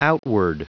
Prononciation du mot outward en anglais (fichier audio)
Prononciation du mot : outward